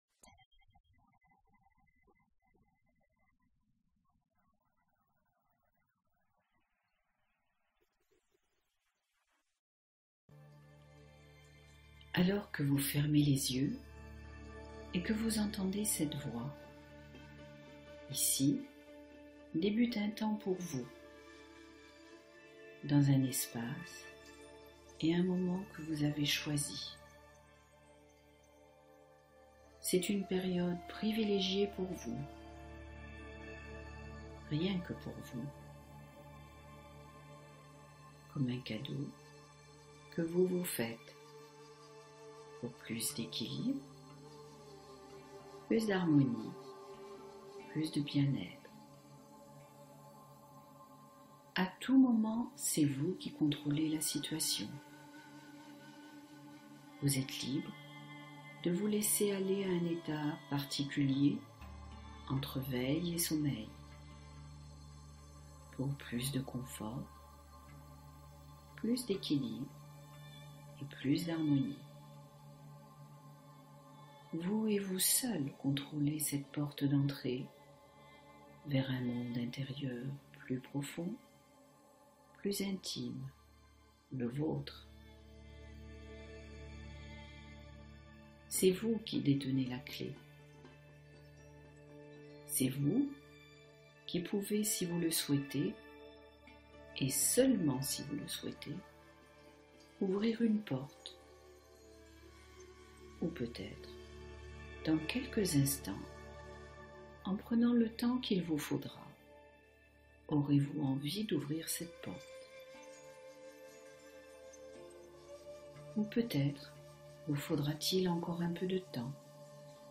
Sommeil retrouvé : relaxation guidée pour s’endormir